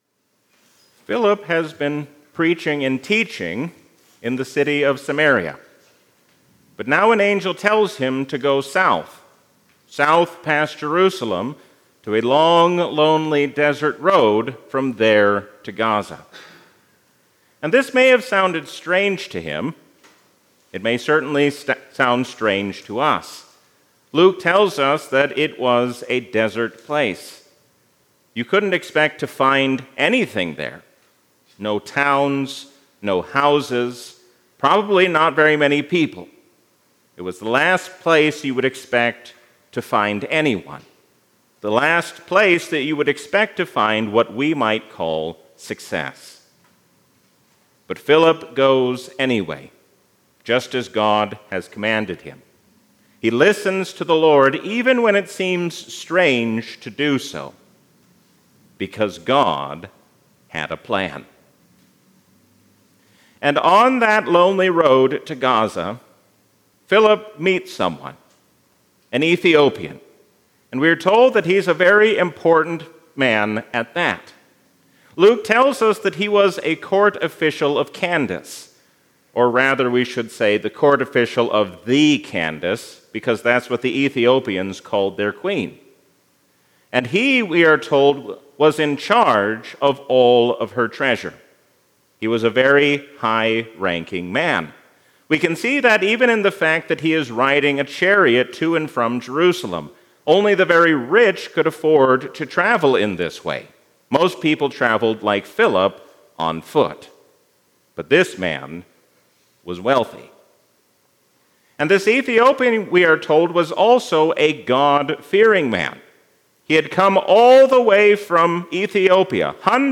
A sermon from the season "Easter 2025." Faith is not just what we know to be true, but how we respond to that truth.